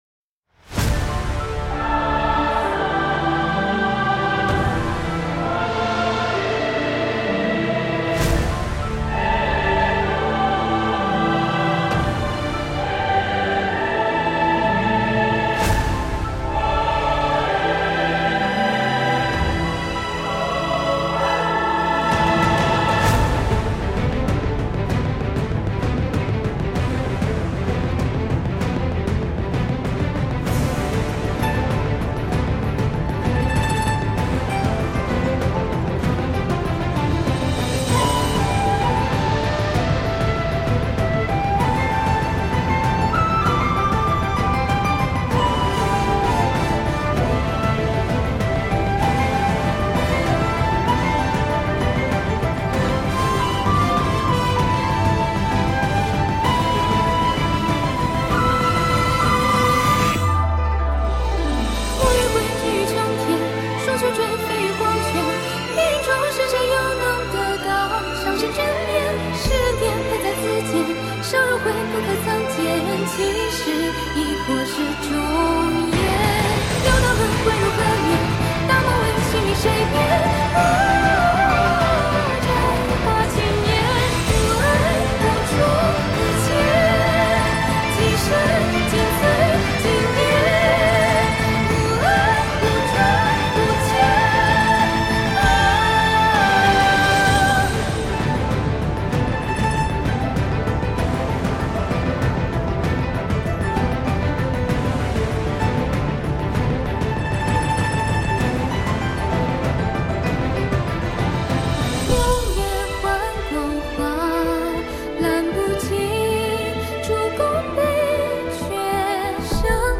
弦乐 Strings
铜管组 Brass
吉他 Guitar
笛/箫 Dizi/Xiao
古筝 Guzheng
合唱 Chorus
人声录音棚 Vocal Recording Studio：杭州栩栩如声录音棚
民乐录音棚 Ethnic Instruments Recording Studio: 九紫天诚录音棚